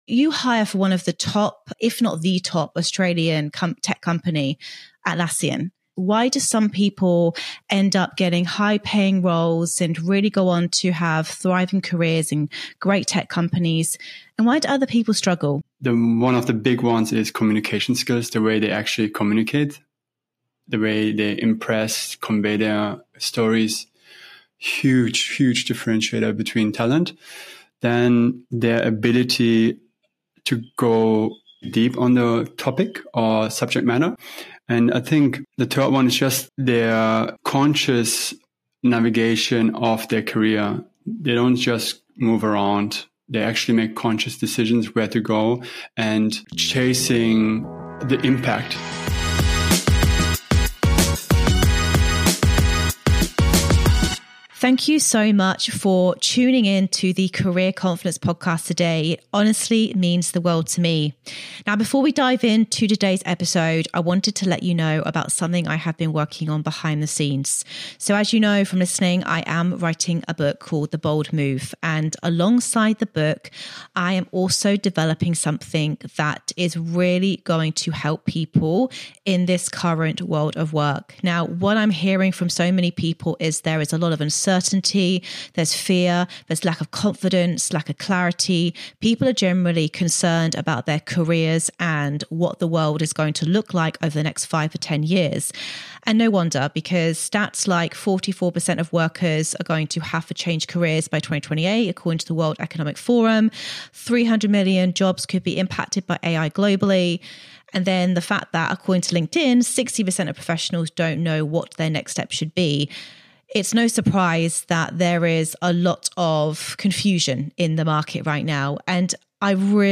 Whether you're applying for jobs, building your LinkedIn, or wondering how AI is changing recruitment, this is a brutally honest conversation about what actually works, what hiring managers look for, and the small shifts that will radically increase your chances of getting hired.